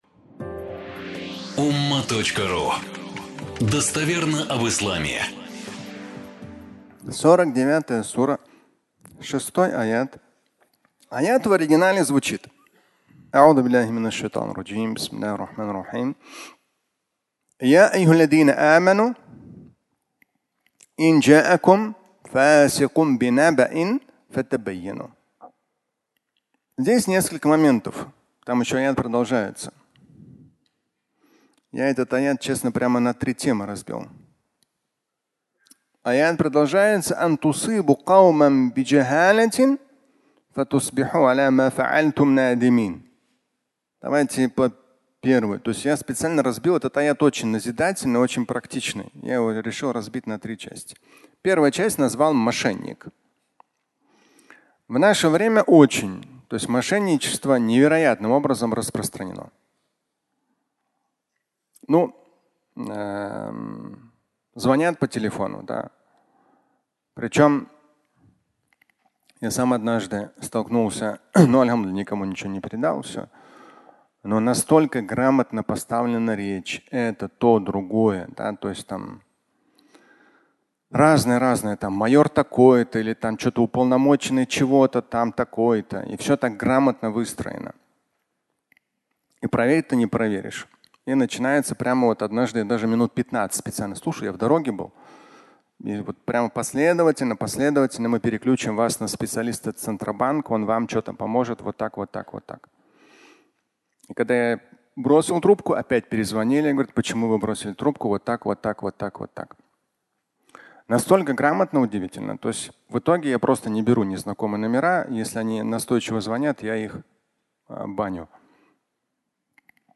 Мошенник (аудиолекция)